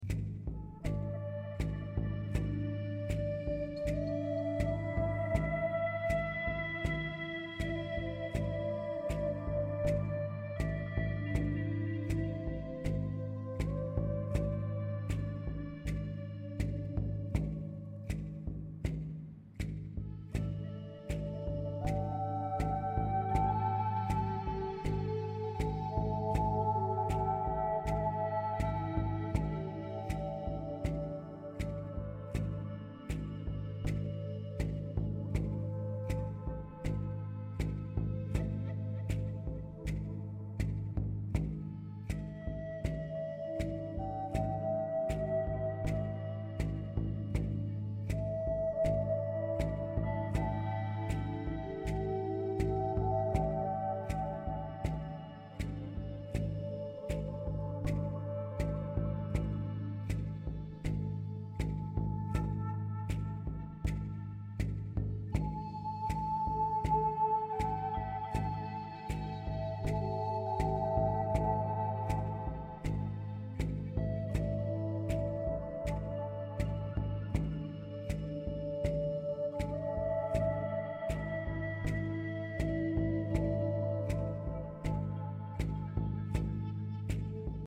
Tre suoni antichissimi, Flauto nativo,